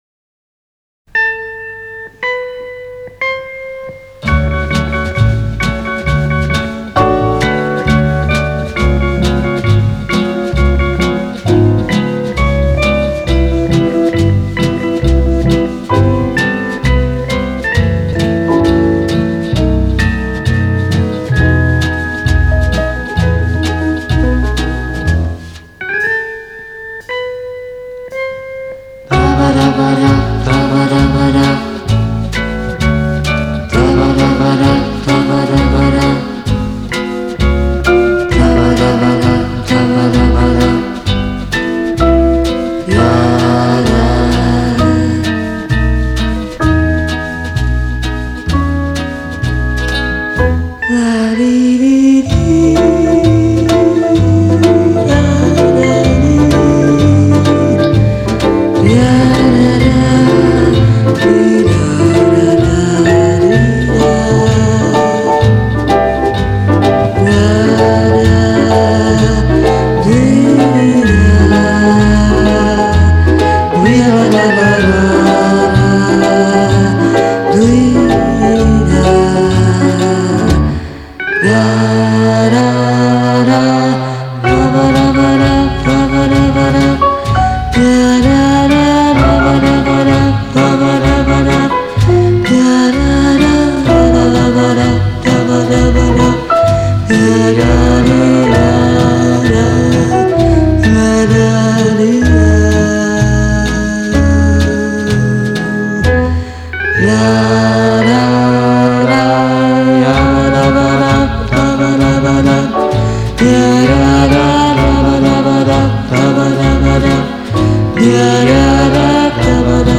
(orch.)